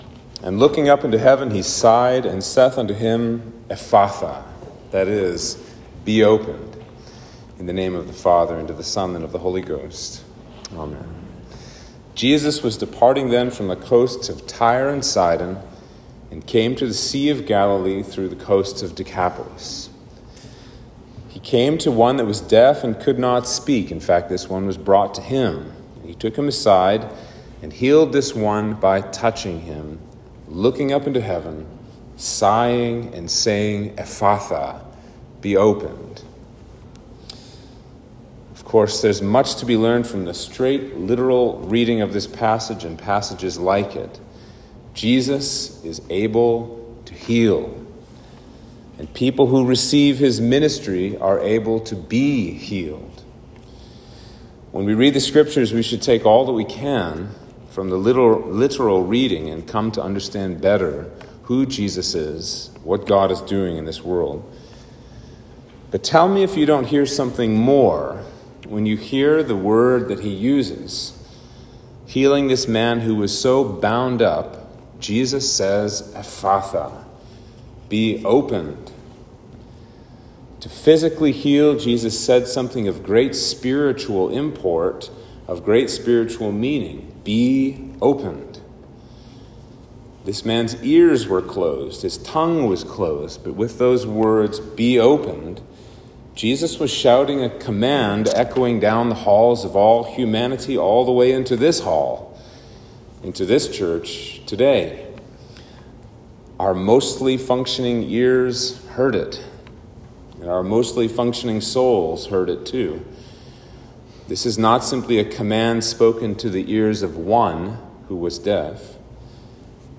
Sermon for Trinity 12